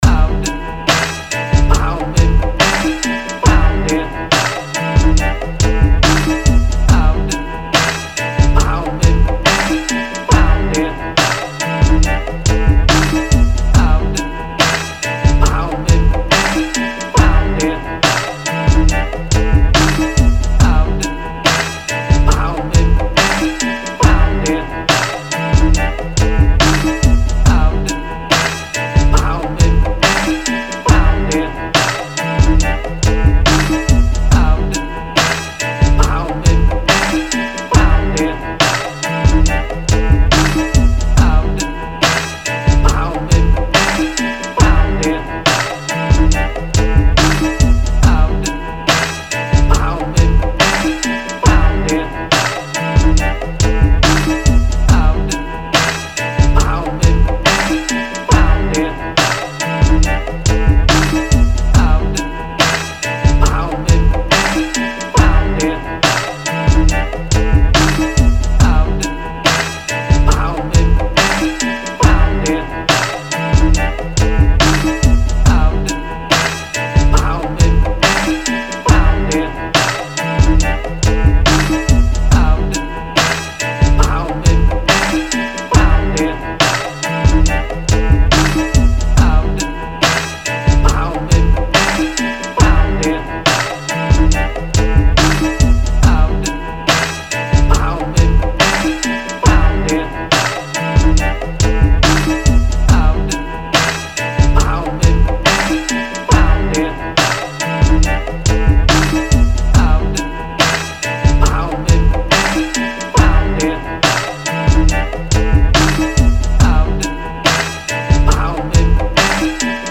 0 Thumb Up 미디어 듣기 통샘플링두번째곡입니다 ㅠ_ㅠㅠ 0 Scrap This!